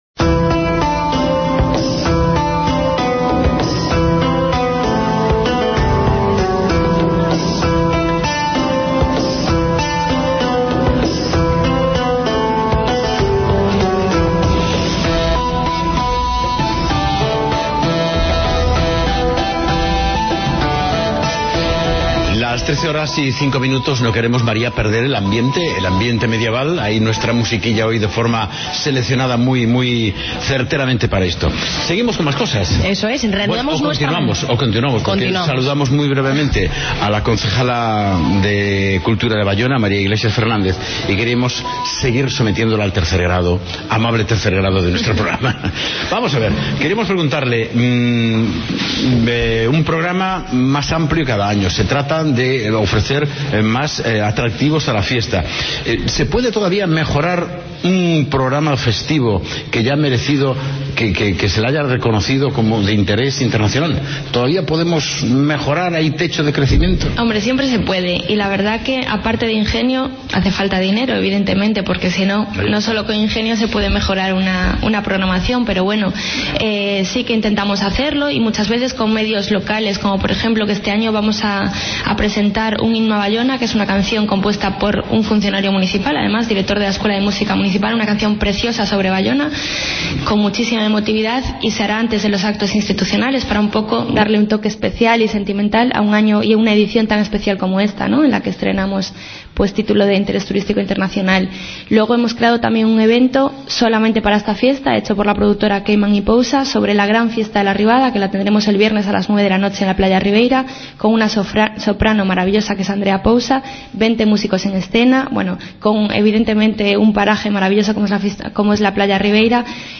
AUDIO: Continuamos con el programa especial, desplazados al concello de Baiona.